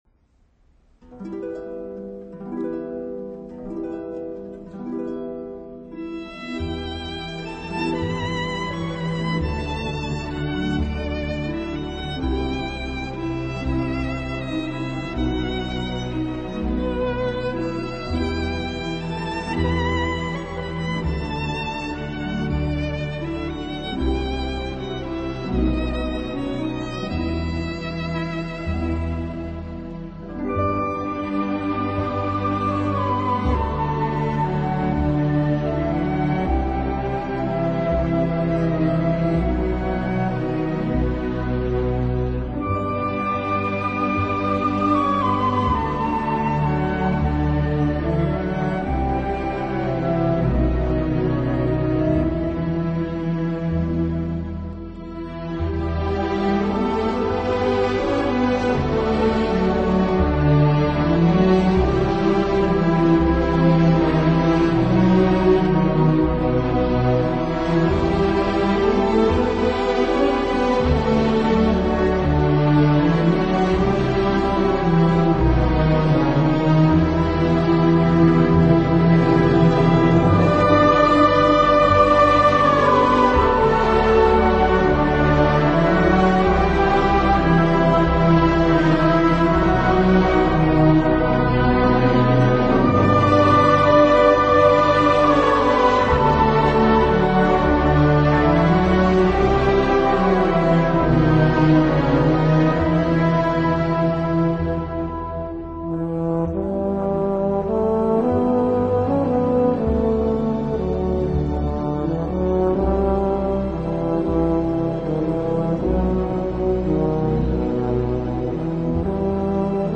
小提琴专辑
音乐类型：Classic 古典
音乐风格：Classical,Waltz